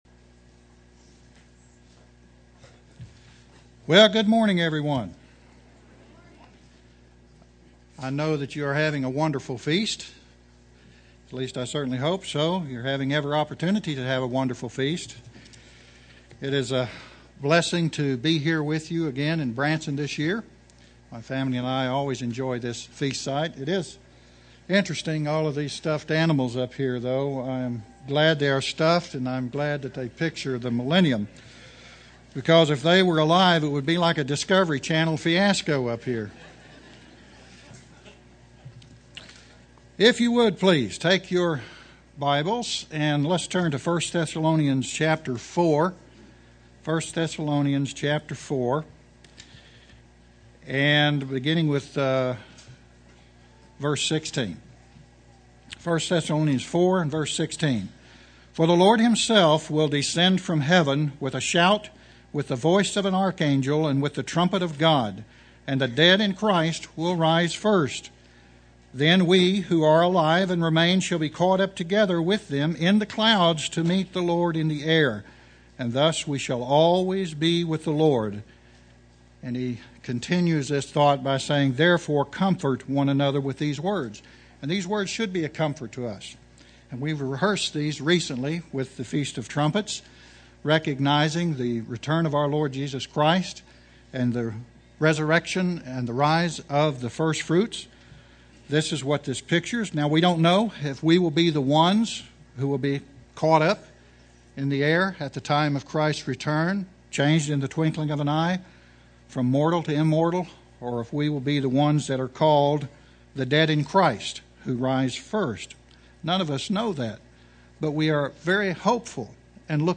This sermon was given at the Branson, Missouri 2011 Feast site.